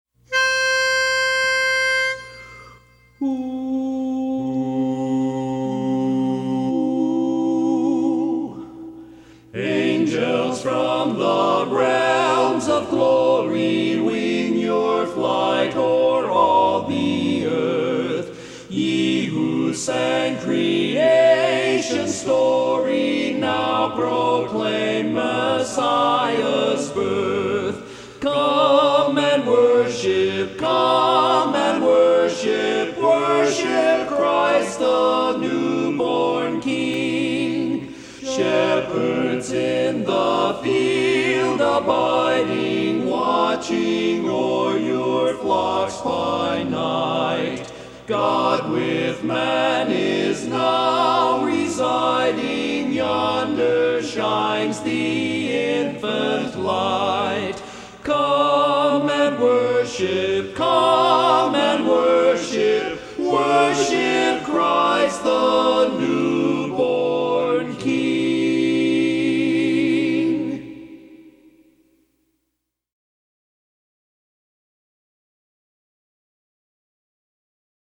Barbershop